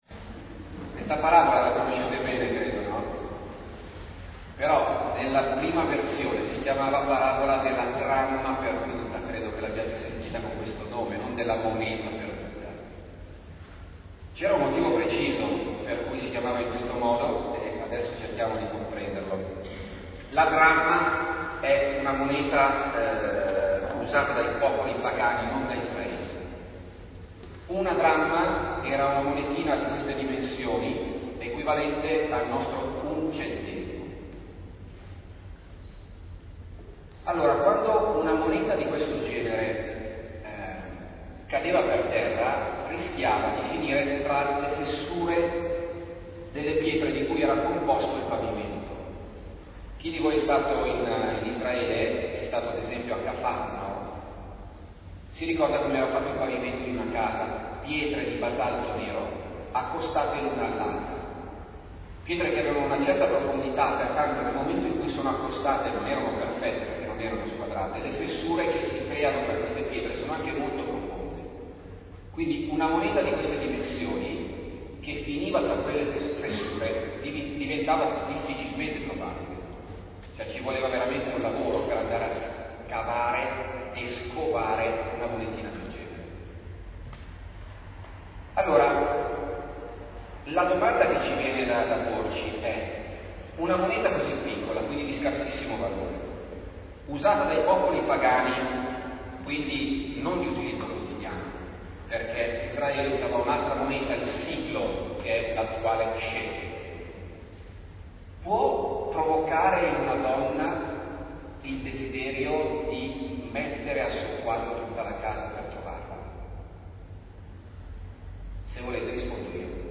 Lectio in Santuario